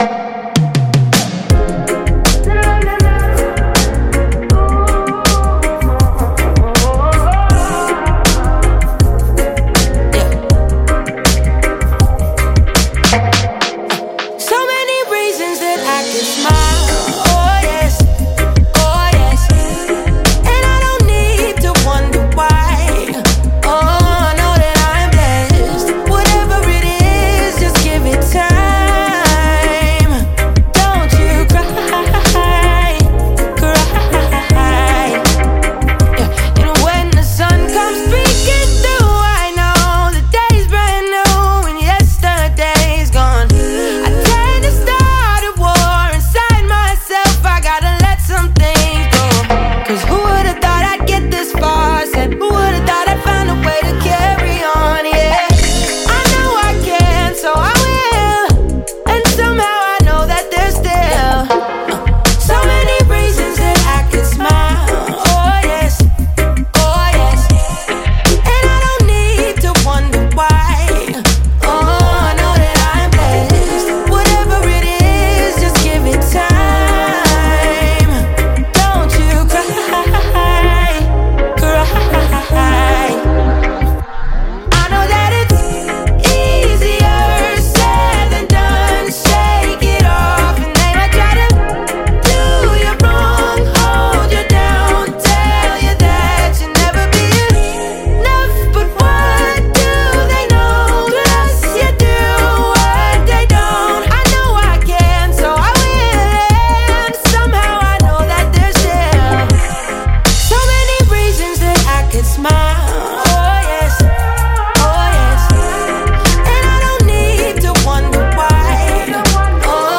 Reggae Single